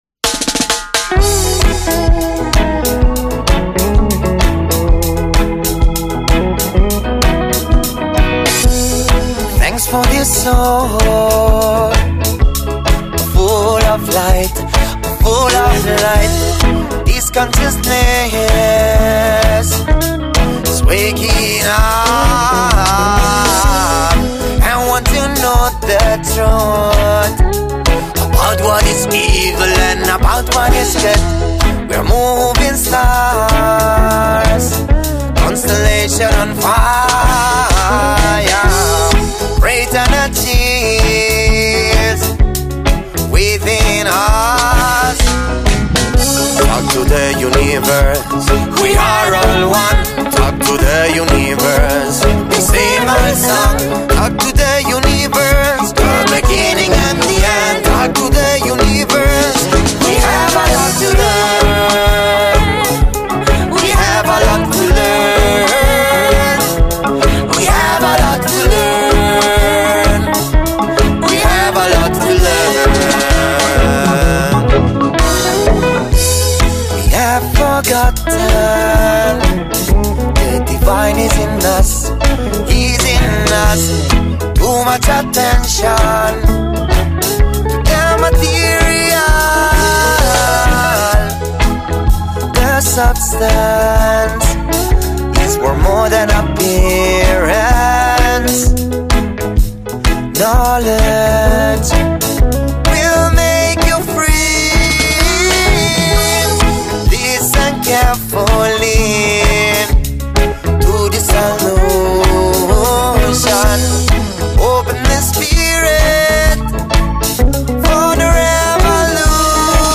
ARTISTA A LA VISTA | INTERVISTA LOVE IN LIONS | Radio Città Aperta
Artista-a-la-Vista-Intervista-Love-in-Lions.mp3